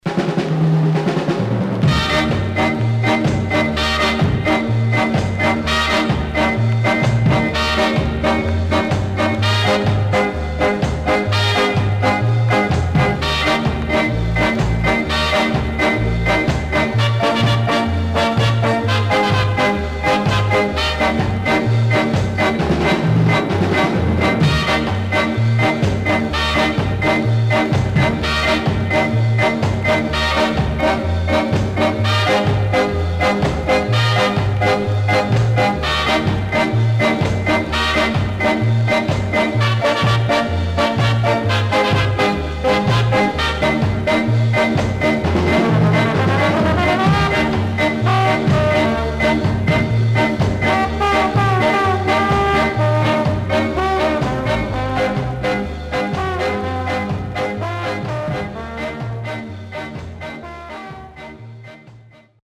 Must!Small Hiss
SIDE A:薄くヒスノイズ入ります。